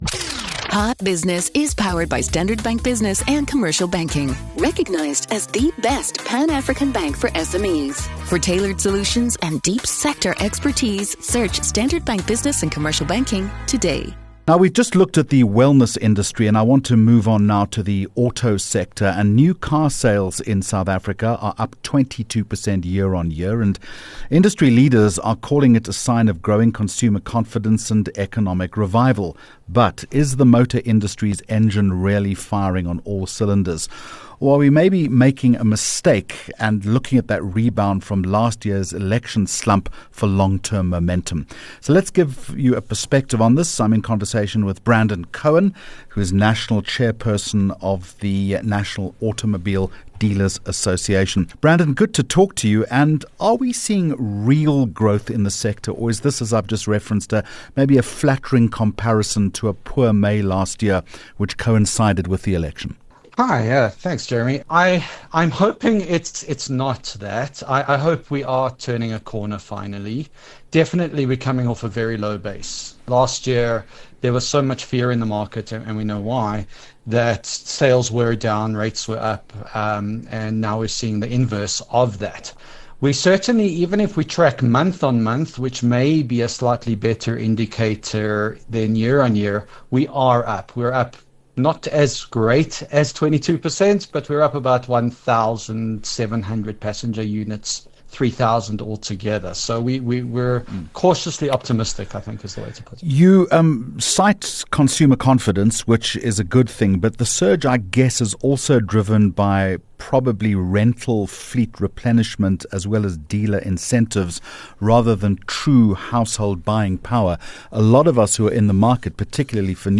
4 Jun Hot Business Interview